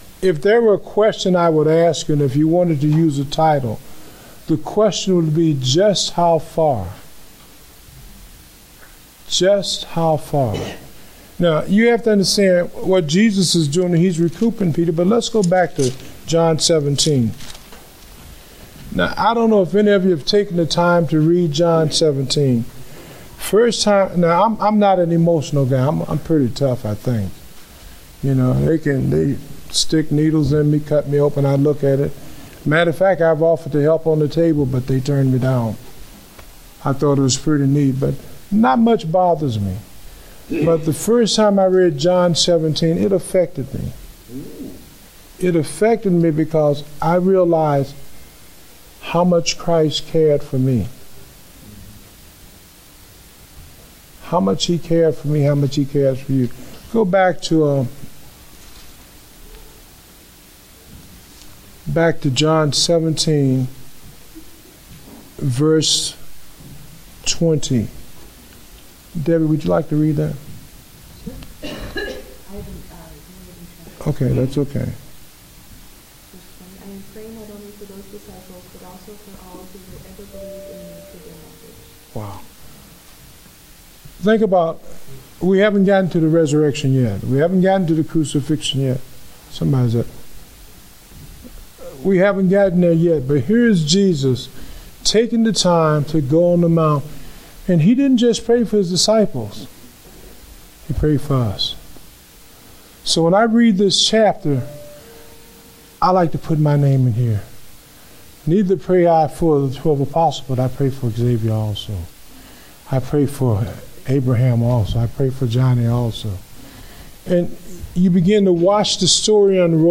Date: April 3, 2016 (Adult Sunday School)